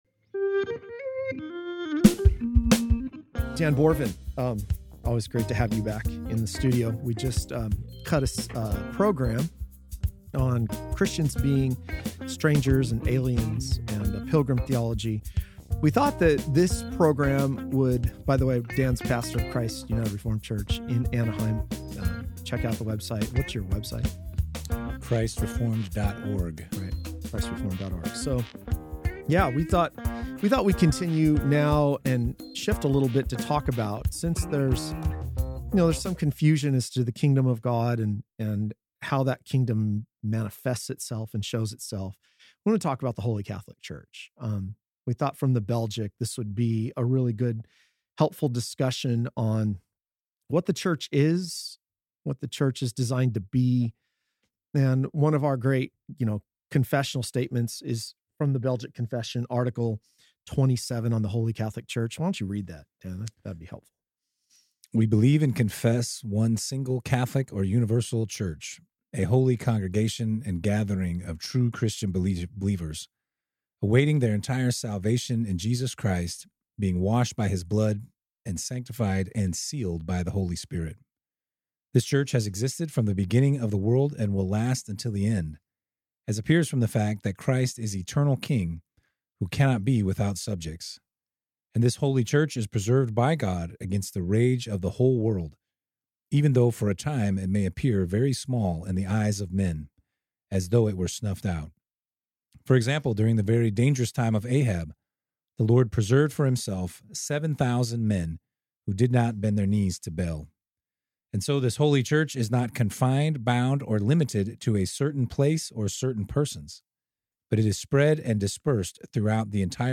back to the AGR studio for a discussion on the holy Catholic church and its profound significance for believers.